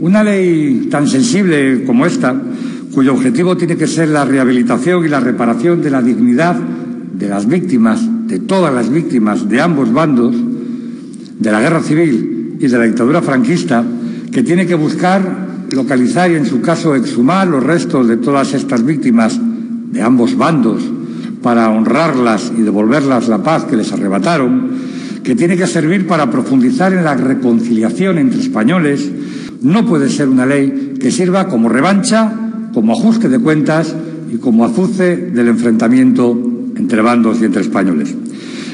Es lo que ha sostenido hoy en rueda de prensa el portavoz de Ciudadanos (Cs) en el Parlamento de Cantabria, Félix Álvarez, después de que su grupo parlamentario haya presentado una enmienda a la totalidad al texto presentado por el bipartito PRC-PSOE.